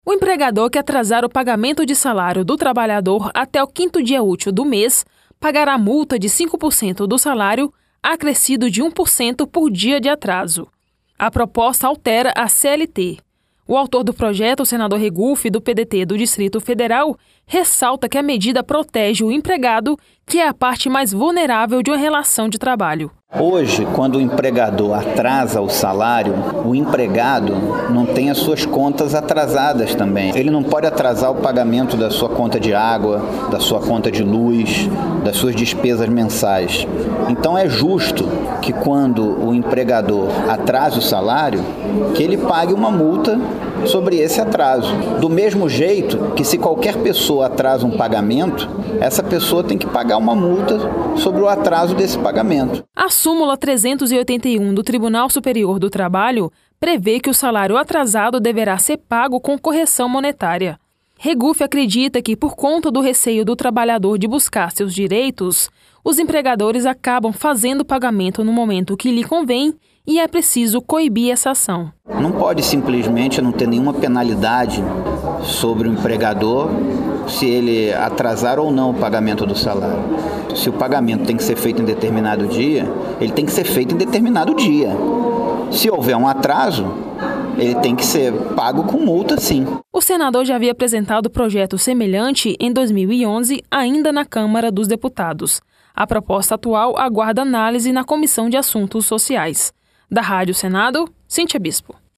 O senador ressalta que a medida protege o empregado, que é a parte mais vulnerável de uma relação de trabalho. Você pode ouvir mais sobre o assunto com a repórter